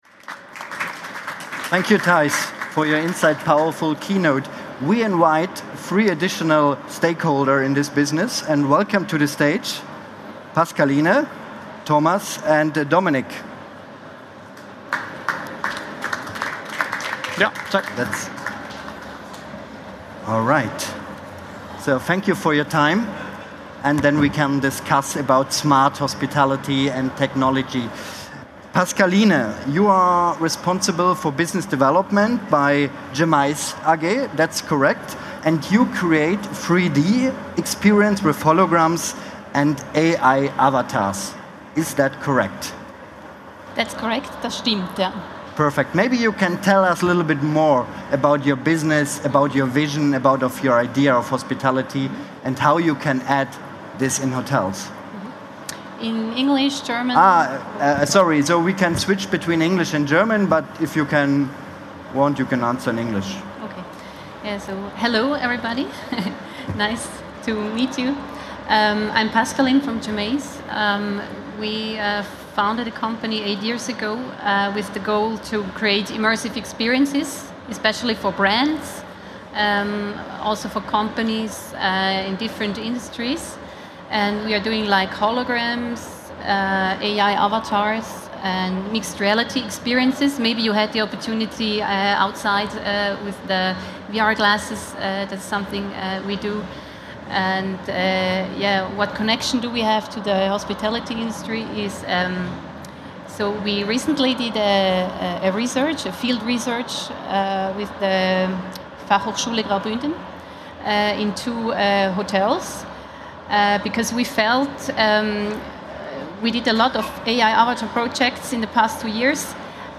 Das Podiumsgespräch fand am 17. November 2025 in den Cheminée Chats der Igeho 2025 statt.